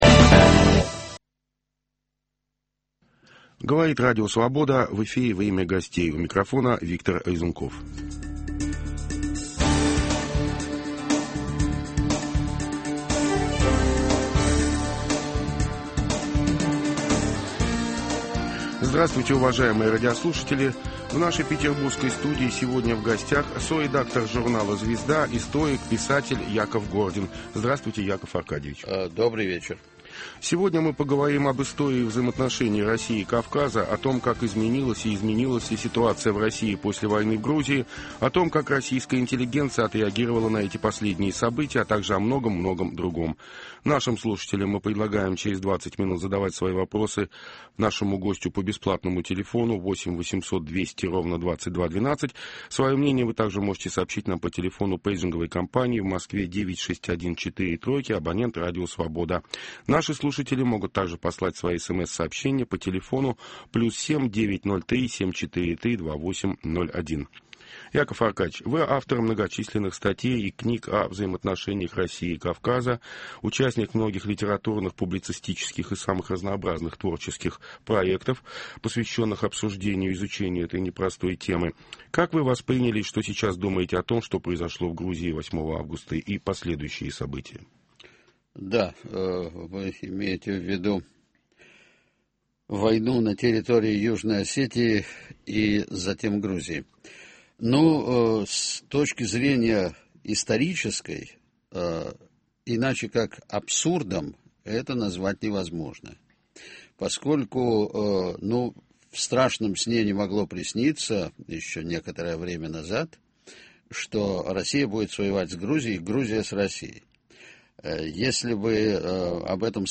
Об этом и многом другом - в беседе с соредактором журнала "Звезда", историком Яковом Гординым.